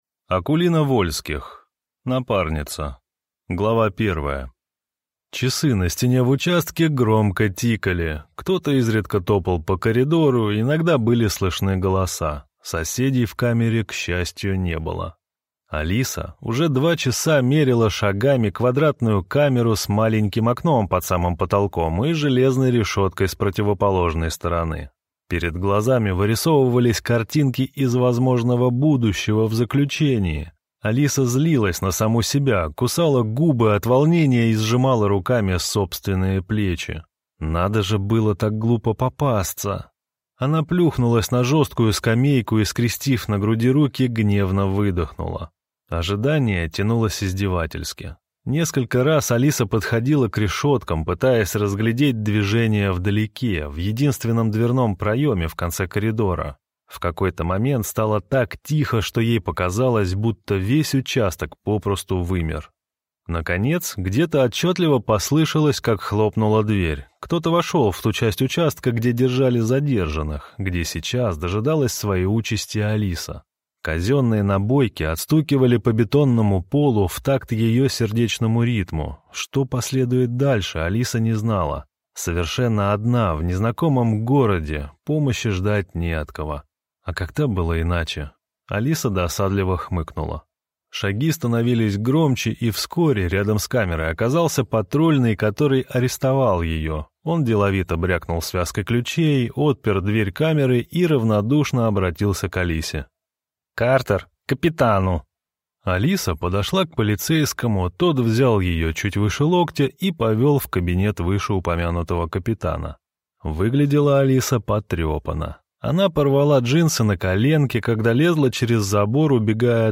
Аудиокнига Напарница | Библиотека аудиокниг